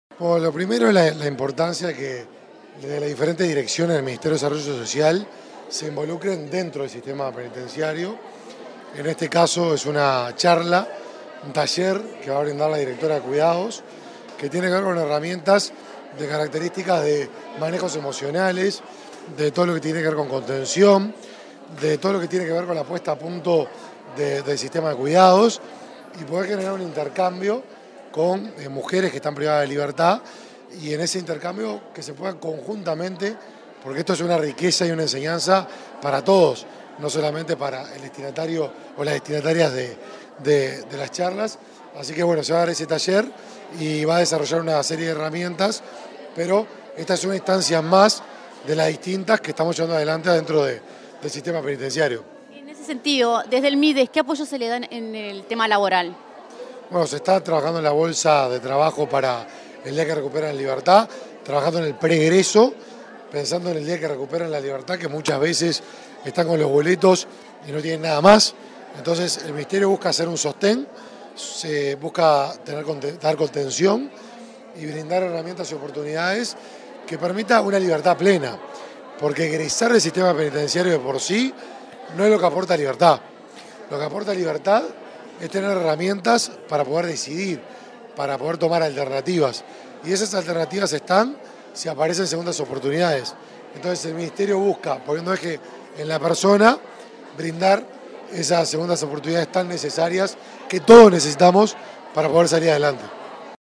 Declaraciones del ministro de Desarrollo Social, Martín Lema
Declaraciones del ministro de Desarrollo Social, Martín Lema 25/05/2023 Compartir Facebook X Copiar enlace WhatsApp LinkedIn Tras participar en el inicio de un ciclo de charlas dirigidas a mujeres privadas de libertad, este 25 de mayo, el ministro de Desarrollo Social, Martín Lema, realizó declaraciones a la prensa.